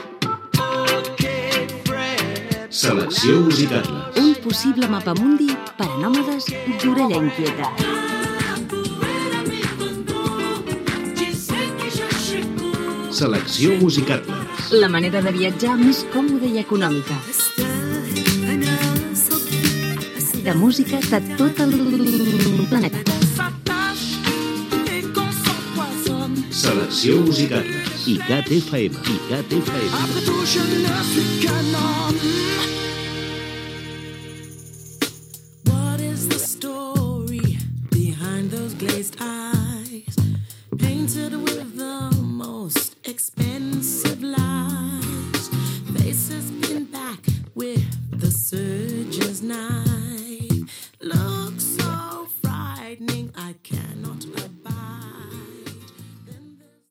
Indicatiu del canal i tema musical